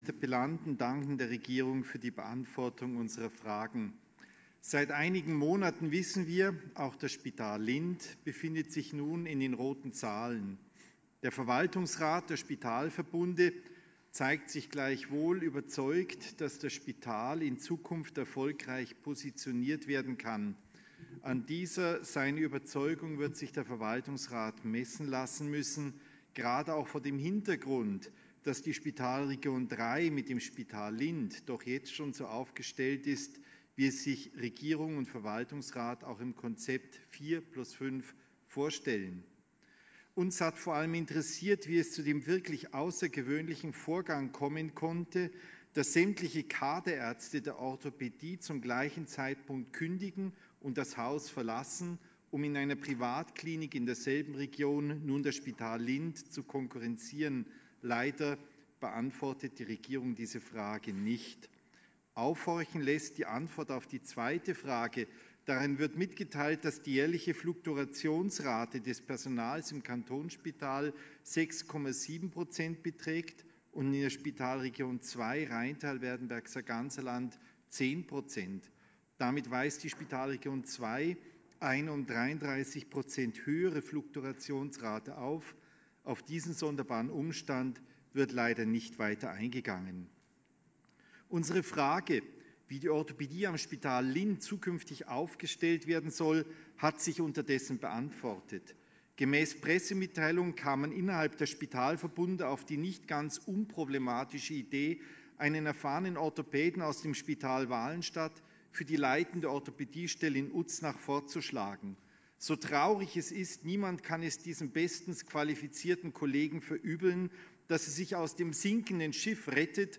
ArtKR Interpellation
18.5.2020Wortmeldung
Session des Kantonsrates vom 18. bis 20. Mai 2020, Aufräumsession